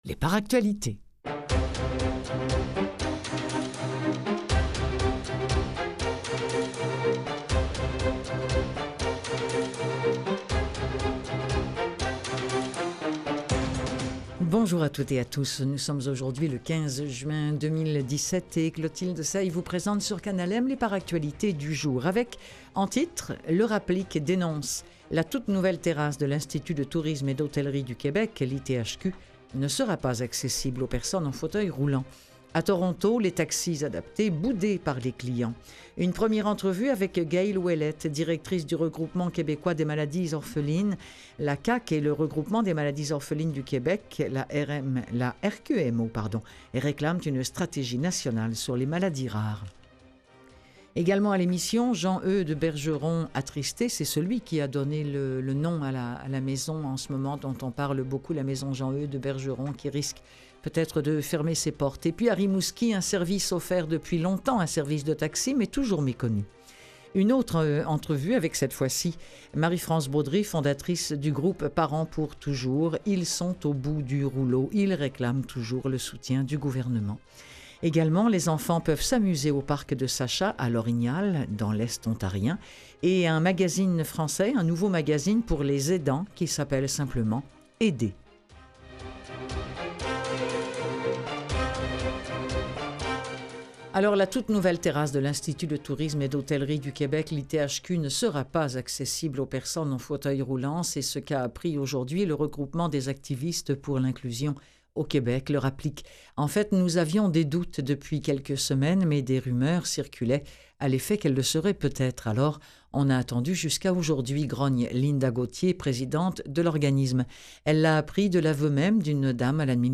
L'ENTREVUE DU JOUR Les parents d’enfants handicapés de plus de 18 ans sont au bout du rouleau et réclament du soutien du gouvernement.